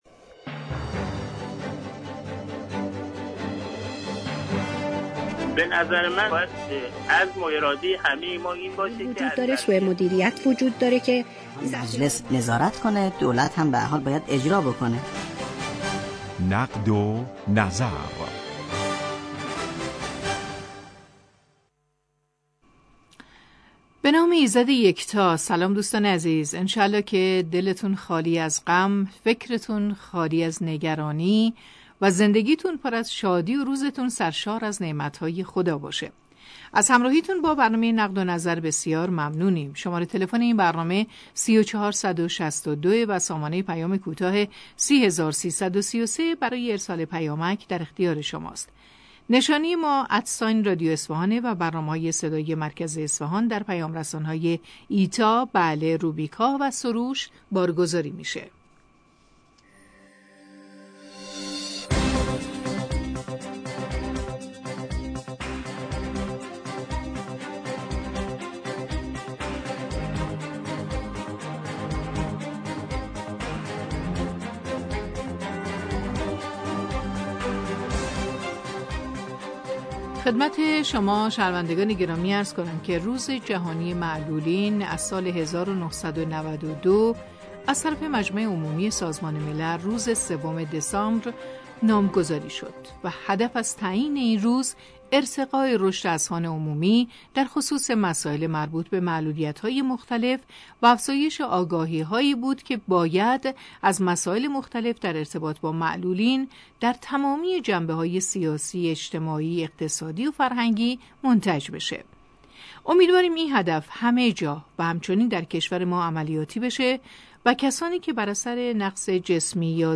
با هم بشنویم| حضور مدیر کل بهزیستی استان و مشاور استاندار در امور معلولین در برنامه رادیویی نقد و نظر
حضور ناصر چاووشی مدیر کل بهزیستی استان و حمیدرضا رادمهر مشاور استاندار در امور معلولین در برنامه رادیویی نقد و نظر